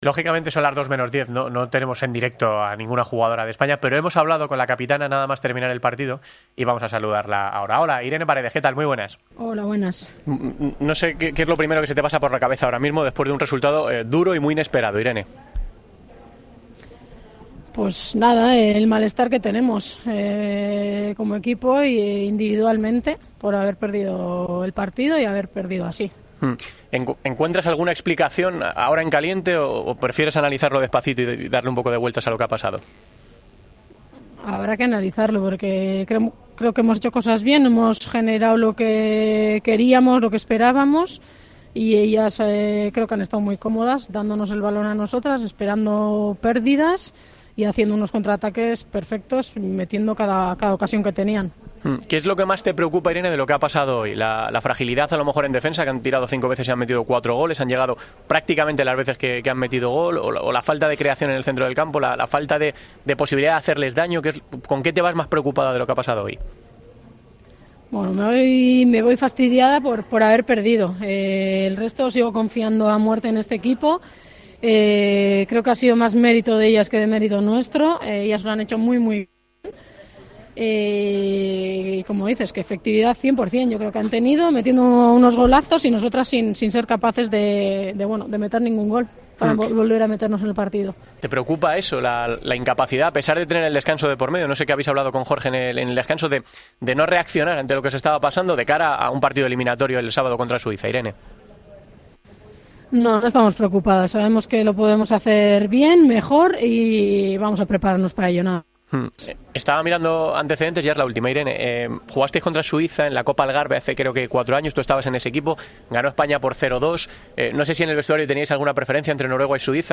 La capitana de la selección española analizó en Deportes COPE la derrota (4-0) ante Japón: "Sabemos que podemos hacerlo mejor y vamos a preparnos para el próximo partido".